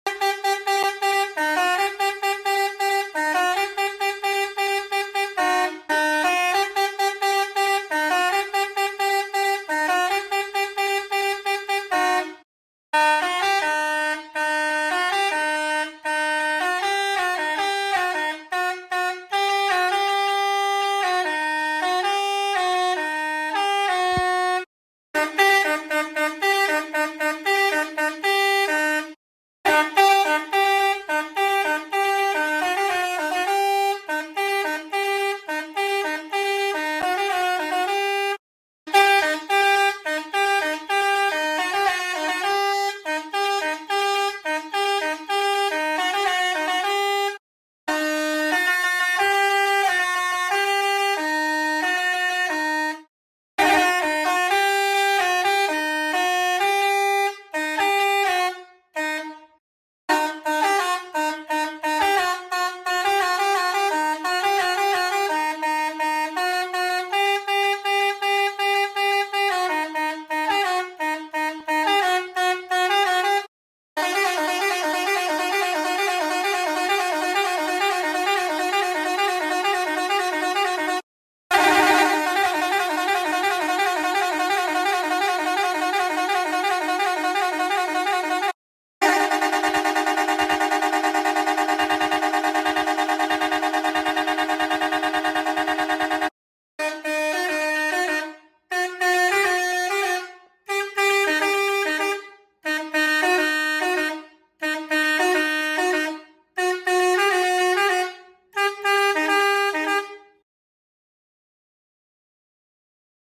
12 Melody Horn Click Here for SOUND!